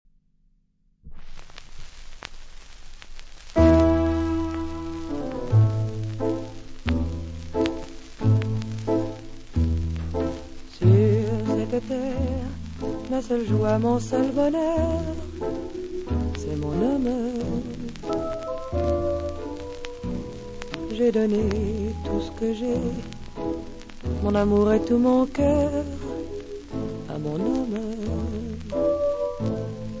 • musica leggera
• Light music